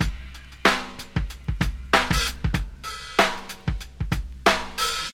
94 Bpm Drum Groove G Key.wav
Free drum groove - kick tuned to the G note. Loudest frequency: 1999Hz
.WAV .MP3 .OGG 0:00 / 0:05 Type Wav Duration 0:05 Size 883,21 KB Samplerate 44100 Hz Bitdepth 16 Channels Stereo Free drum groove - kick tuned to the G note.
94-bpm-drum-groove-g-key-mzB.ogg